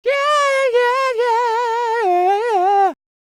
DD FALSET005.wav